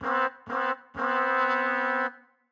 Index of /musicradar/gangster-sting-samples/95bpm Loops
GS_MuteHorn_95-C1.wav